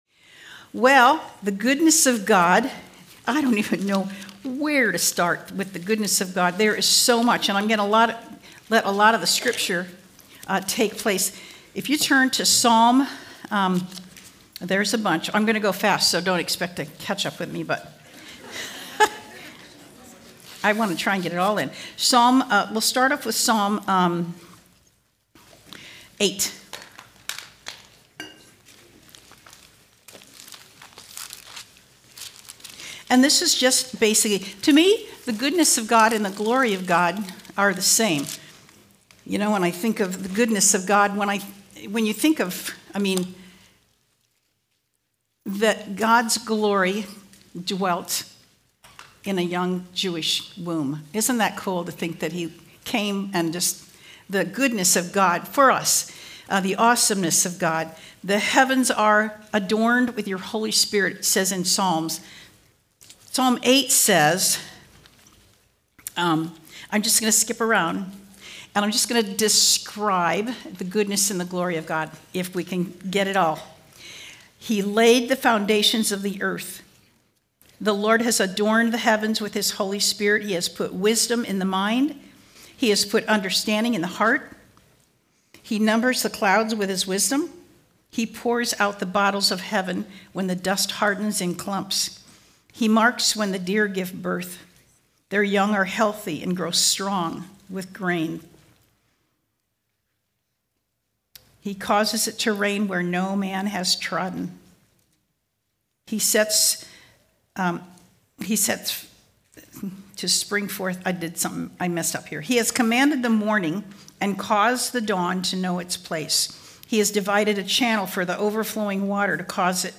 Home » Sermons » Fruit of Goodness
2026 DSWG Conference: Women's Gathering Date